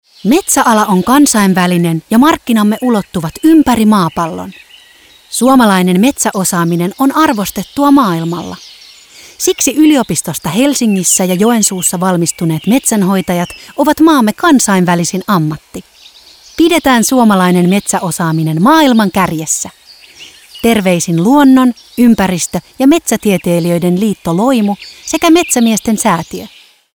Mainokset kuuluvat kaupallisilla radiokanavilla kautta Suomen 13.10. alkaen viikoilla 41–42 ja 44–45.